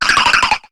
Cri de Balbuto dans Pokémon HOME.